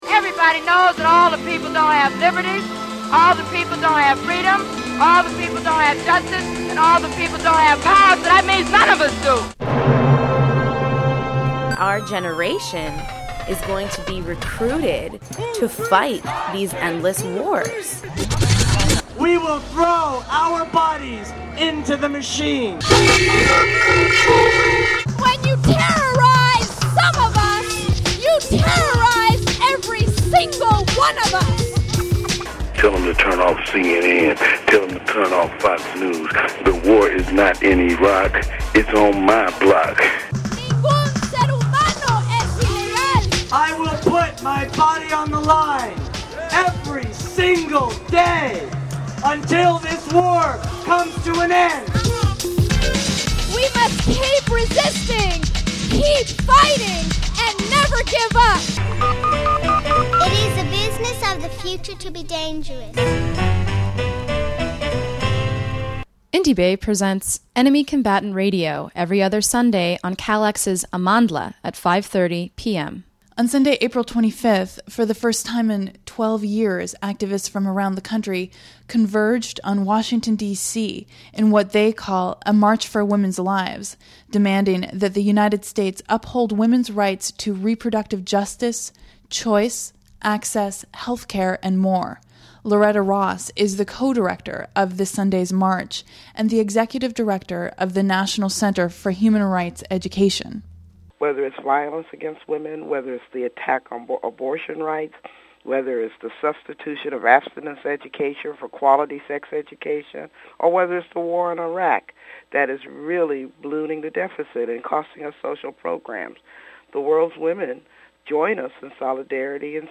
and interviews with women of color involved in organizing the March for Women's Lives in Washington DC. 11:25 minutes 6.5 MB